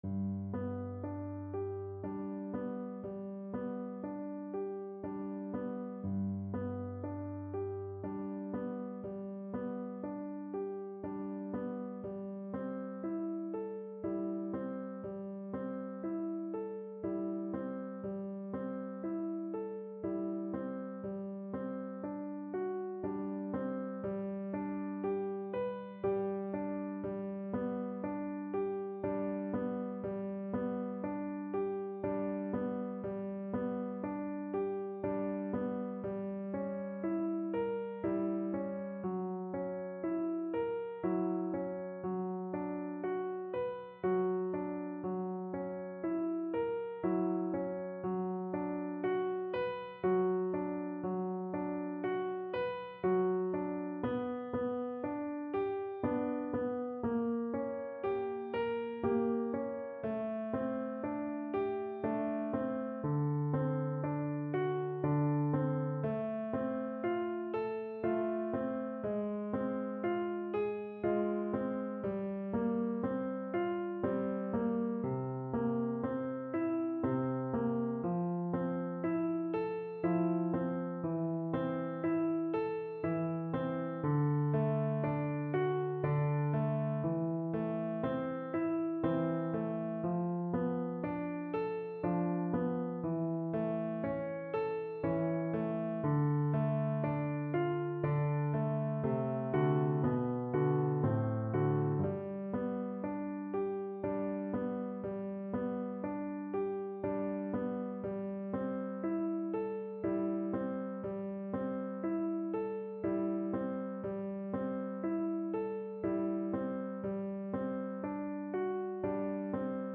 Saint-Saëns: Łabędź (na skrzypce i fortepian)
Symulacja akompaniamentu
saint-saens_labecz_nuty_vn-pf-acc..mp3